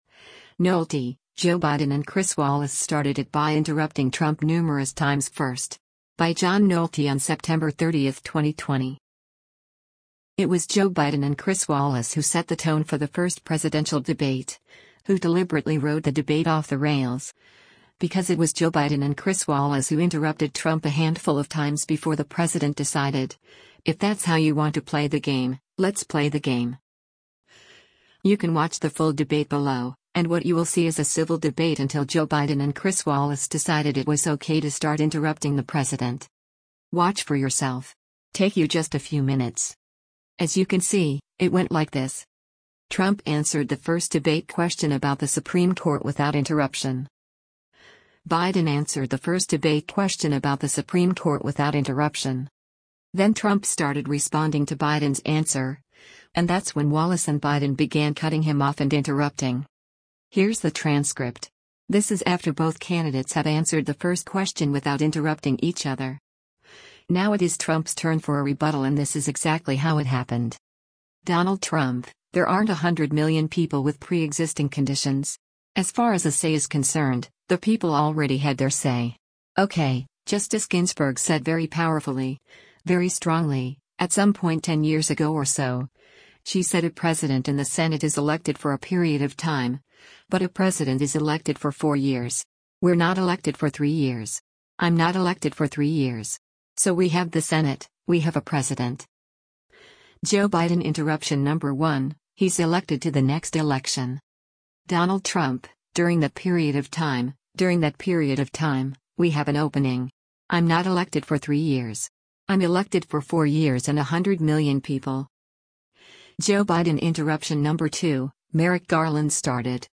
You can watch the full debate below, and what you will see is a civil debate until Joe Biden and Chris Wallace decided it was okay to start  interrupting the president.